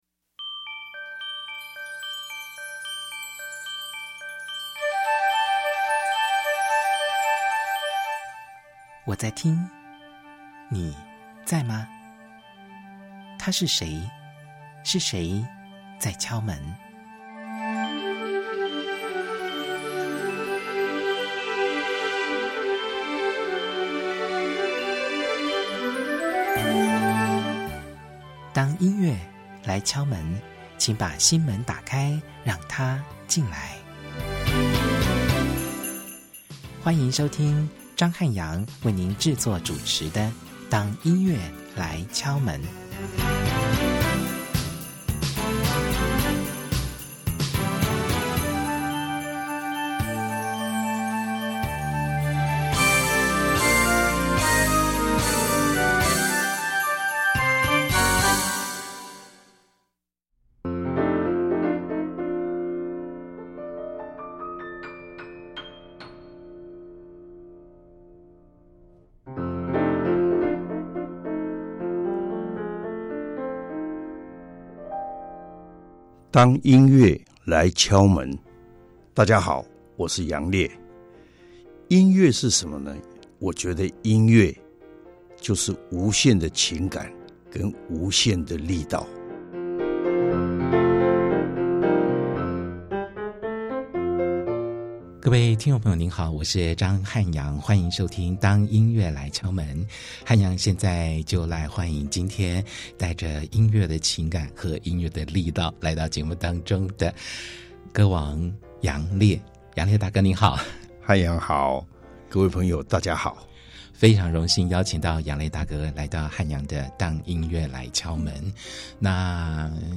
第一單元~本集節目來賓是金鐘歌王楊烈。